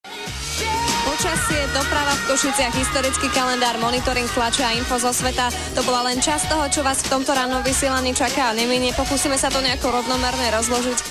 Má príjemný hlas, tvrdá výslovnosť sa v iných vstupoch neprejavila a tak snáď išlo len o jednorázové pošmyknutie.